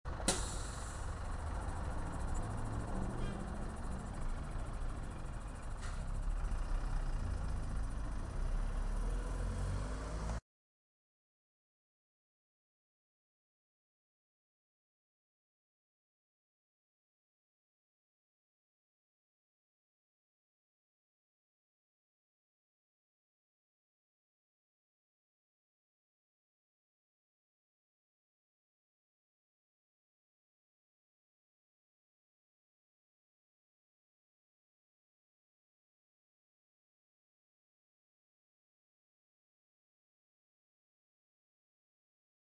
单声道声音，带英文文件名 " 公交车路过
描述：巴士站的巴士经过的声音。
标签： 氛围 公共汽车 通过 驾驶 传递
声道立体声